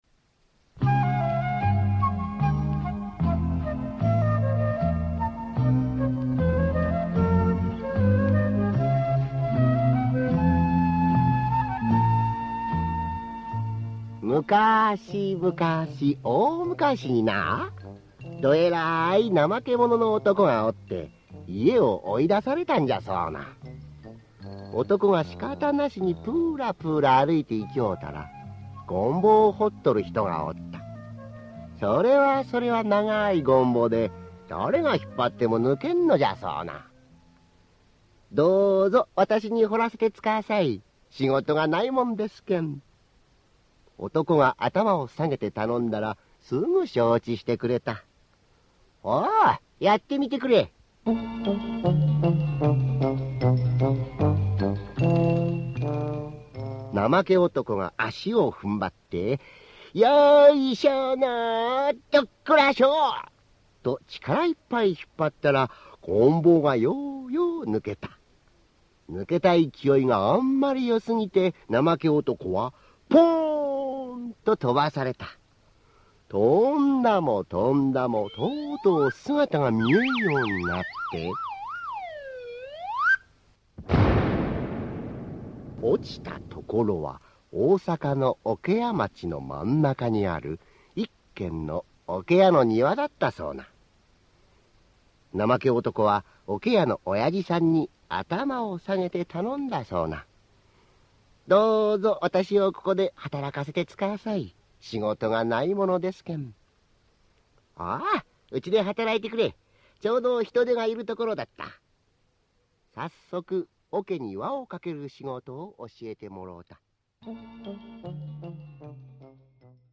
[オーディオブック] なまけ男のふしぎなたび